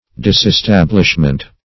Disestablishment \Dis`es*tab"lish*ment\, n.